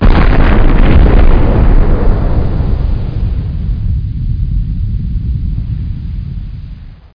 expllar.mp3